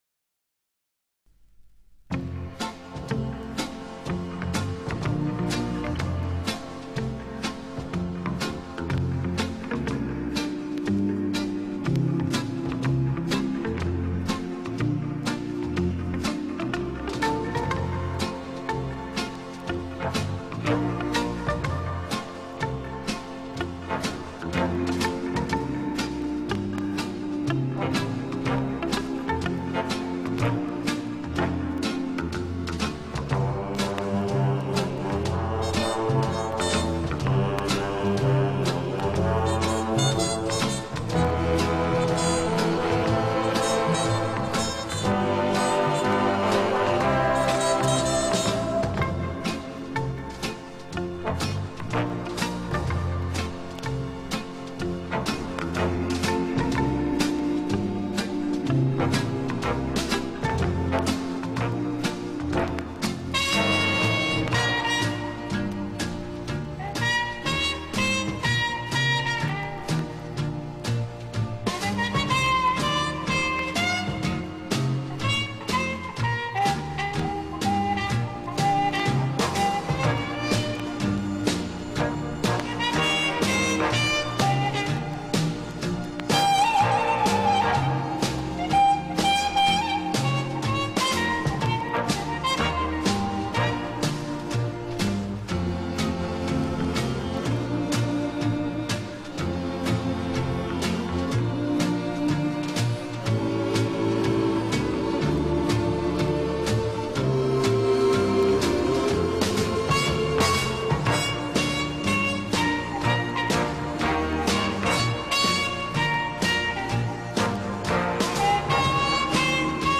Жанр: Instrumental, Classical, Easy Listening